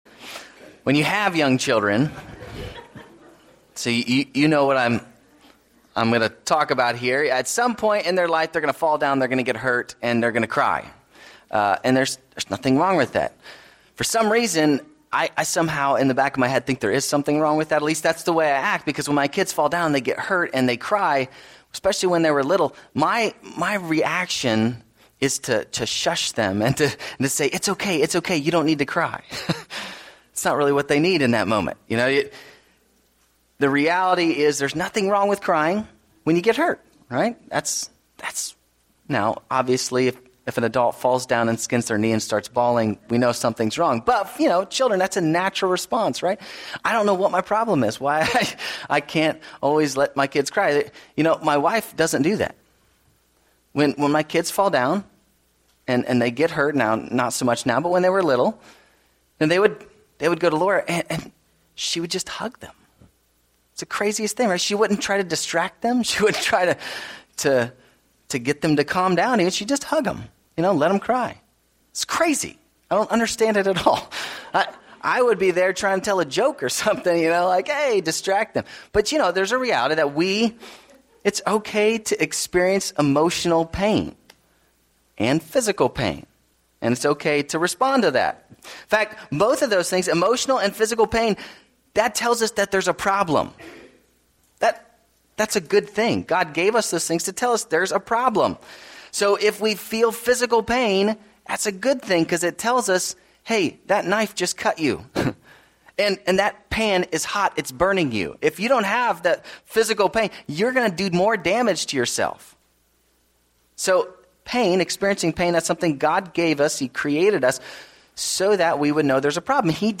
Sermons
Service Type: Sunday 10:30am